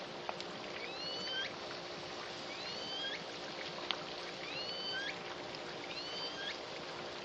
Index of /data/product/SRKW/calls-and-vessel-noise